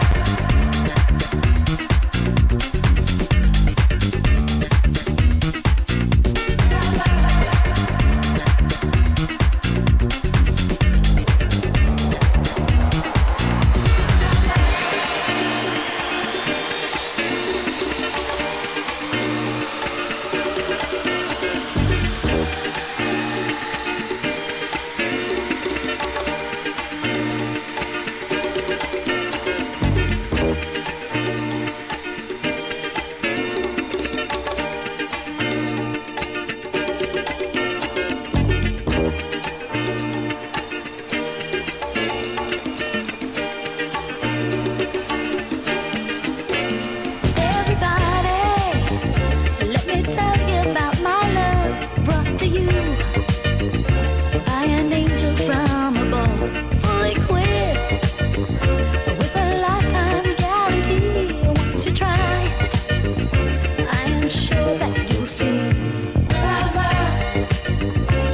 HAOUSE REMIXなんですが、ストリングスから急速にオリジナル音源になり、再加速して再びハウスに戻る展開！！！